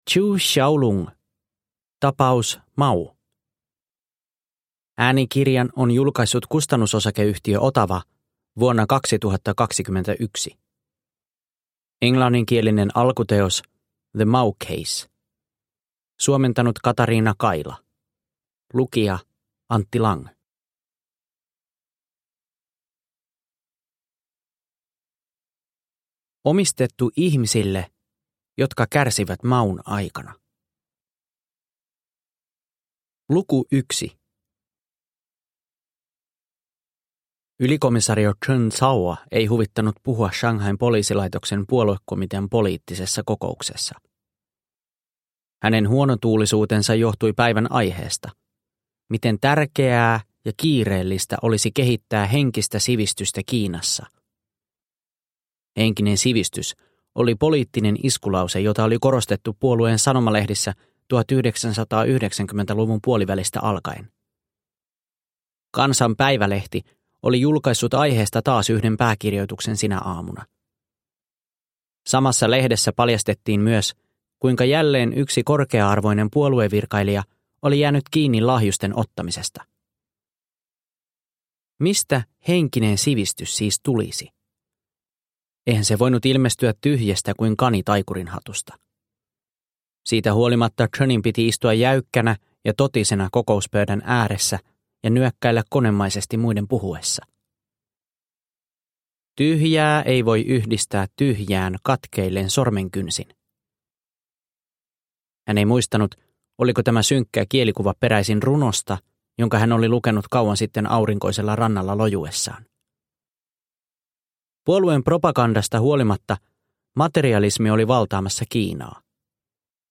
Tapaus Mao – Ljudbok – Laddas ner